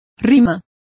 Complete with pronunciation of the translation of rhymes.